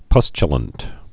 (pŭschə-lənt, pŭstyə-)